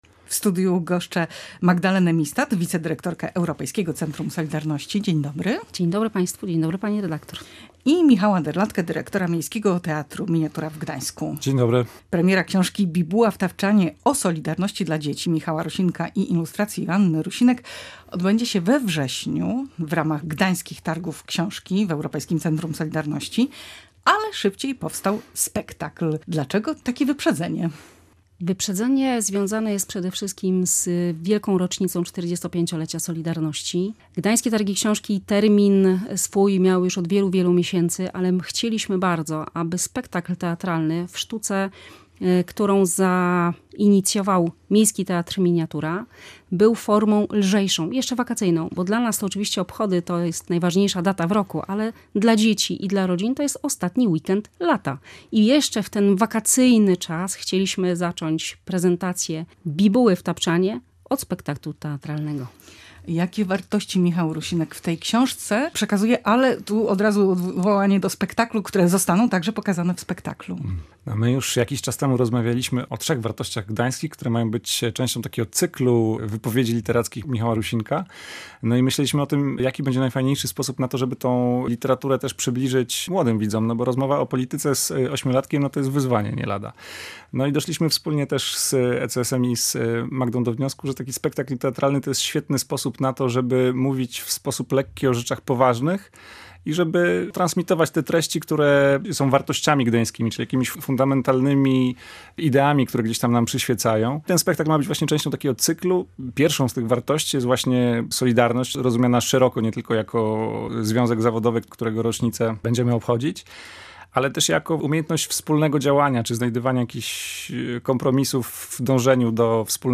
twórcy przedstawienia i przedstawiciele Europejskiego Centrum Solidarności.